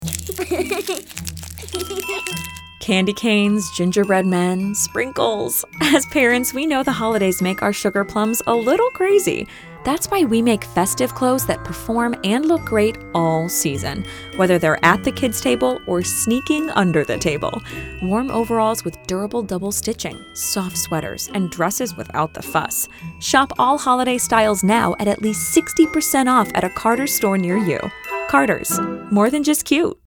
Female
Yng Adult (18-29), Adult (30-50)
Radio Commercials
1120Carter_s_Holiday_Spotify_Ad.mp3